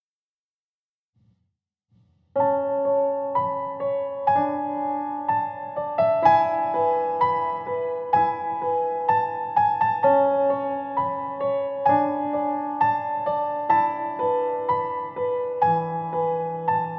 Virtual Piano
I've been battling with getting a pure, rich, clean and crisp sounding piano for ages, I've tried EQs, compression, different pianos, but they always sound flat, dull, muddy or tinny.
One is the original without editing, the other is edited.